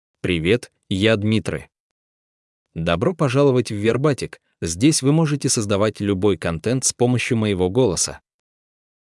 MaleRussian (Russia)
Dmitry — Male Russian AI voice
Voice sample
Listen to Dmitry's male Russian voice.
Dmitry delivers clear pronunciation with authentic Russia Russian intonation, making your content sound professionally produced.